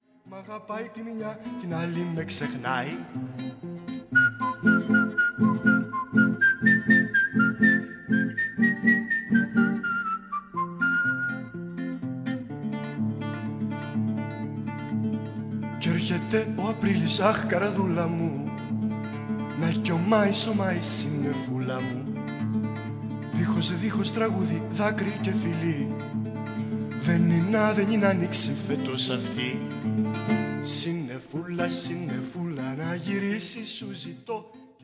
Τραγουδά ο συνθέτης